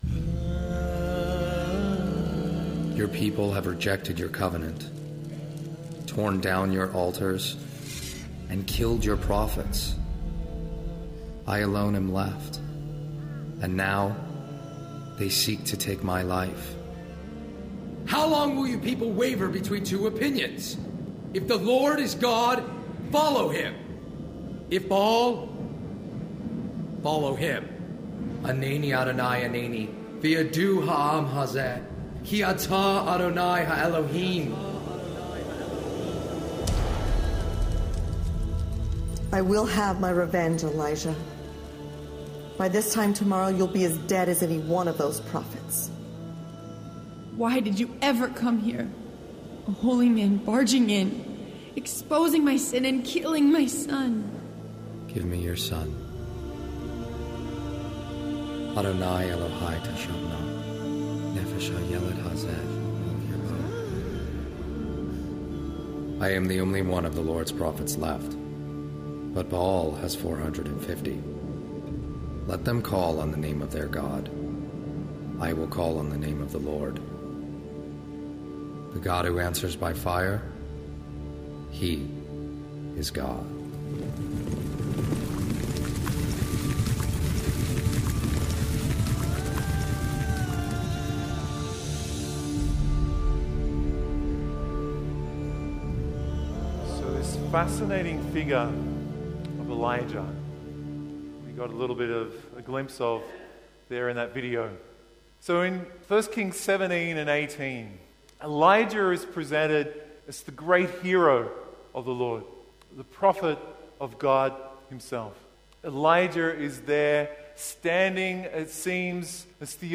Download or Play MP3 MP3 Audio file (Sunday Vigil Mass)
The audio at the start of the homily is from the video "Elijah" by Dan Stevers